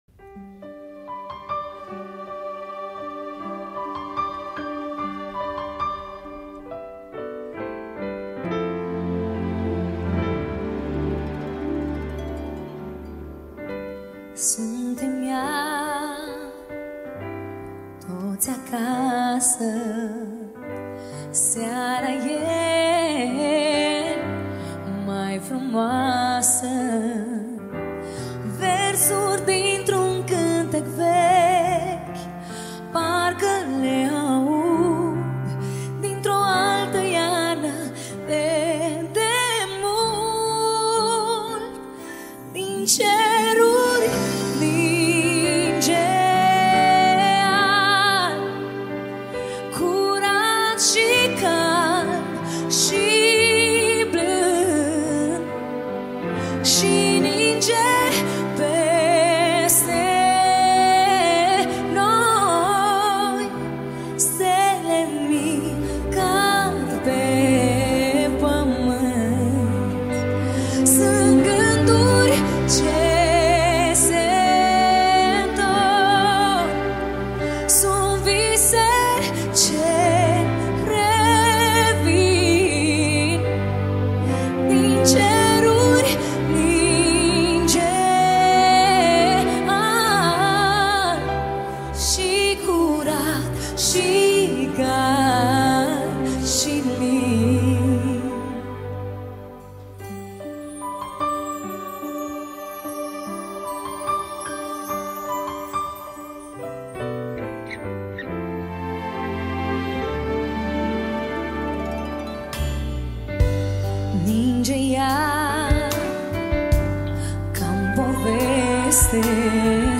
live La Opera Nationala
Data: 12.10.2024  Colinde Craciun Hits: 0